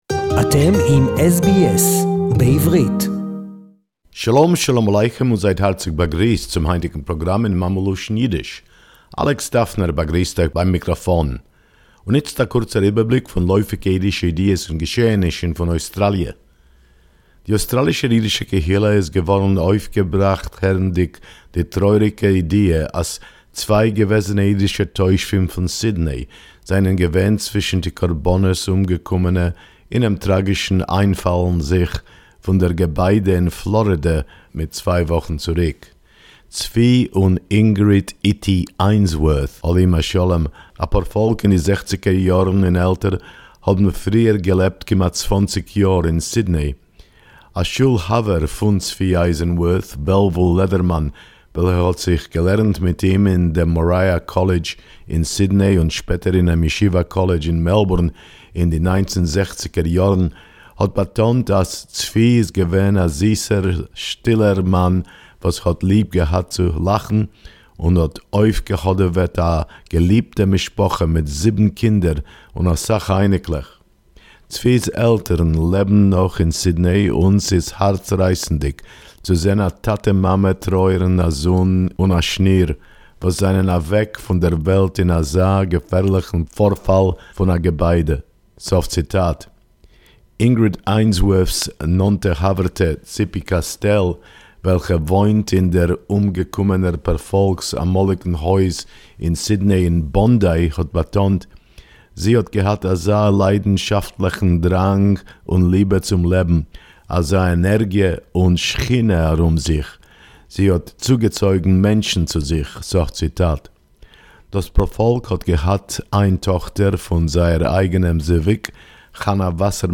SBS Yiddish report